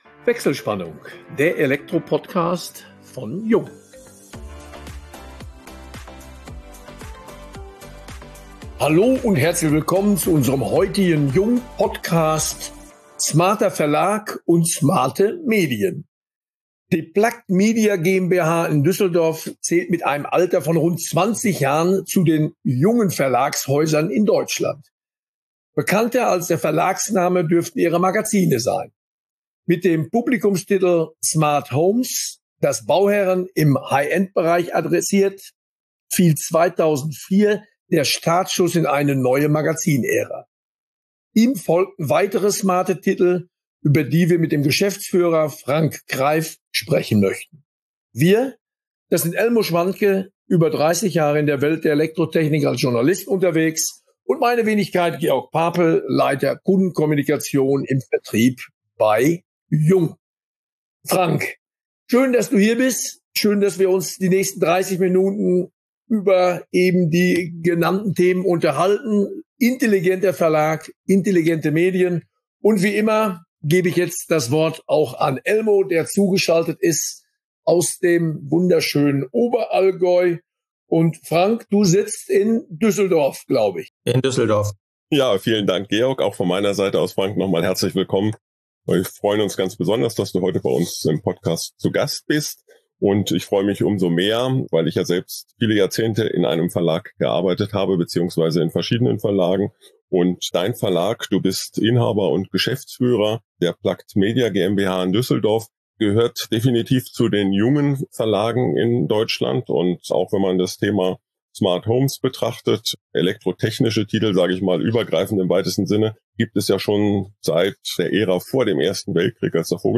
In vertrauensvollen Gesprächen diskutieren die Moderatoren